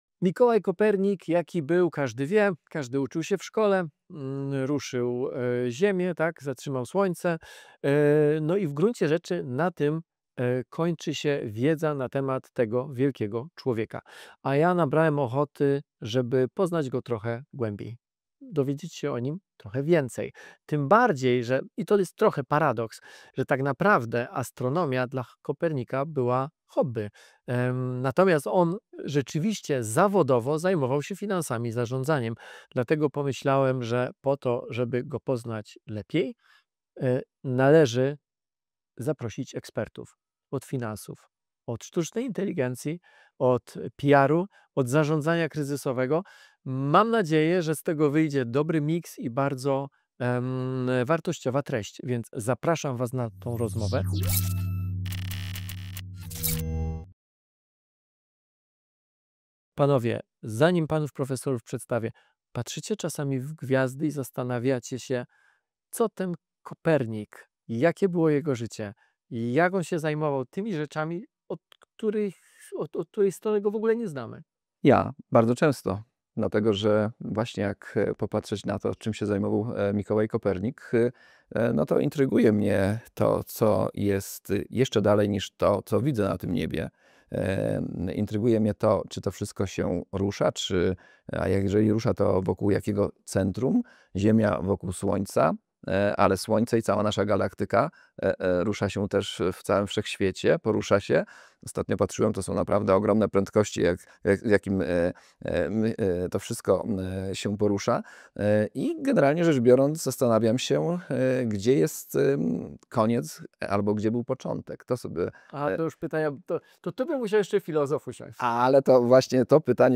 W debacie Akademii Kopernikańskiej rozmawiamy o inflacji, wartości waluty, emocjach, które wpływają na decyzje finansowe, a także o tym, jaką rolę odgrywają dziś technologia i sztuczna inteligencja. To rozmowa o współczesności, ale prowadzona przez pryzmat pytań, które okazują się starsze, niż mogłoby się wydawać.